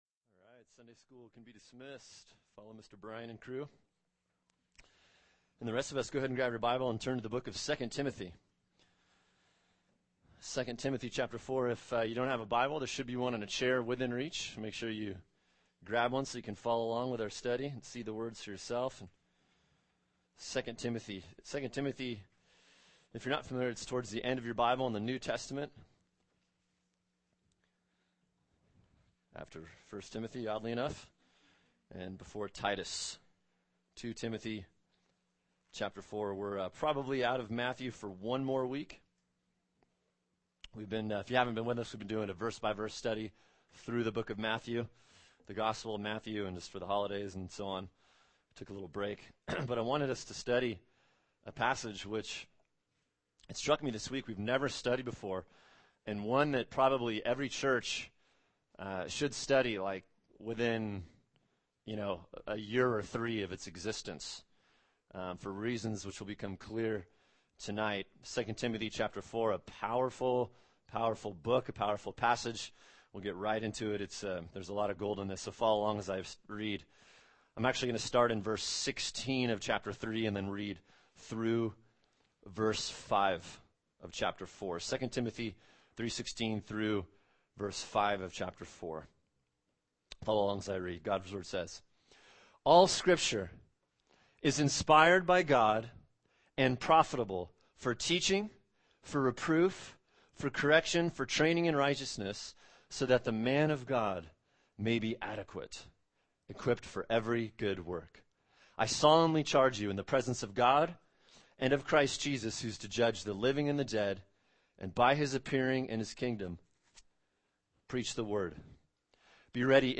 [sermon] 2 Timothy 4:1-4 “What Your Pastor Must Do” | Cornerstone Church - Jackson Hole